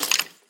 sounds / mob / skeleton / step3.mp3